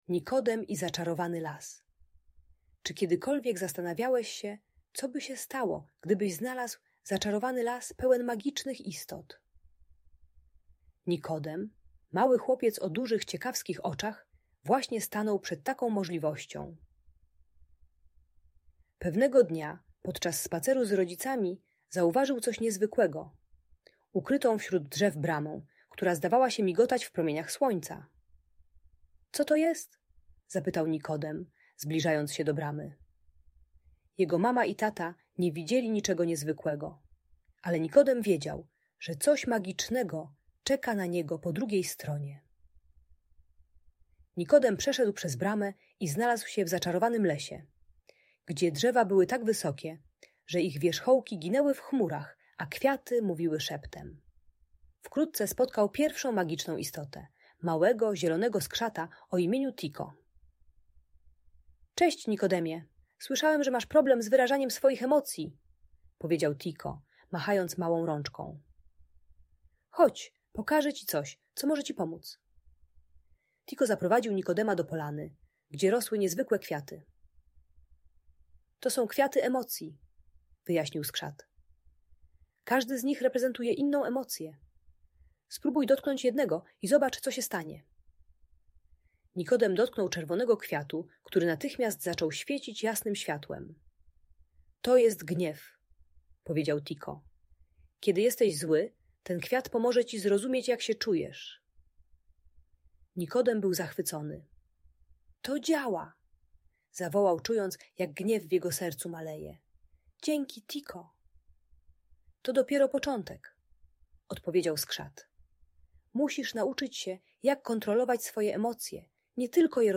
Nikodem i Zaczarowany Las - Audiobajka